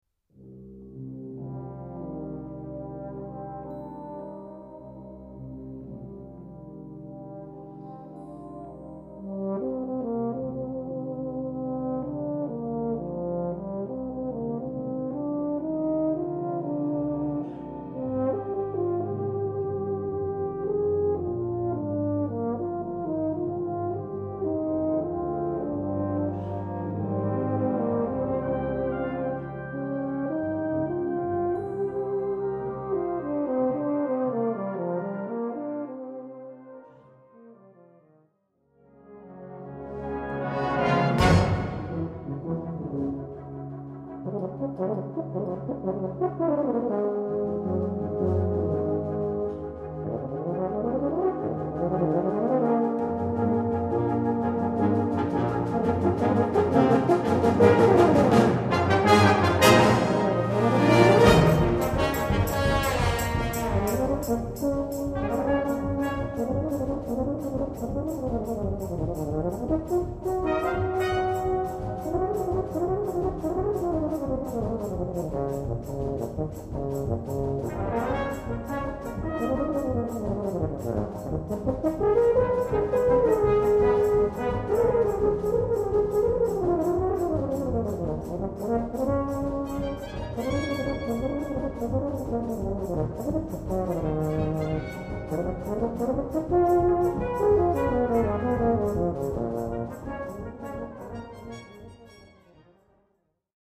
Baryton ou Tuba et Brass Band